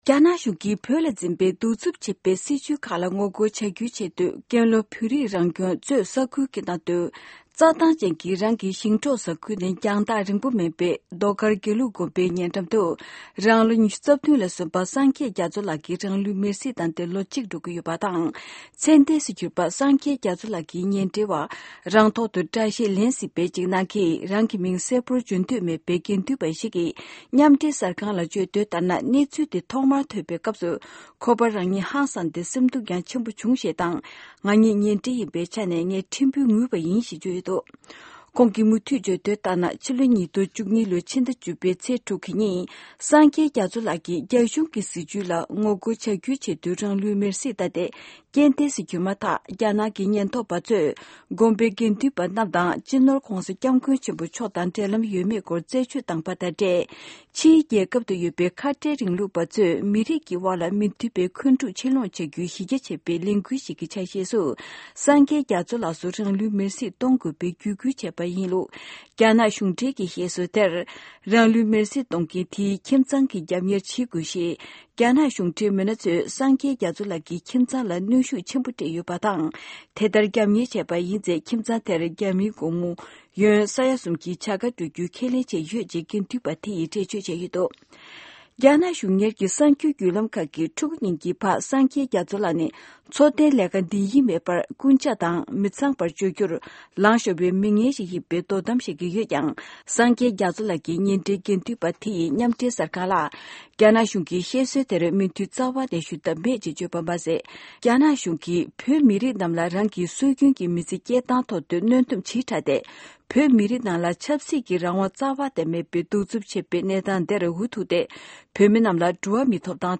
ཕྱོགས་བསྒྲིགས་ཀྱིས་སྙན་སྒྲོན་ཞུས་པ་ཞིག་གསན་གྱི་རེད།